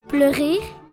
uitspraak
pleurire-pron.mp3